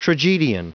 Prononciation du mot : tragedian
tragedian.wav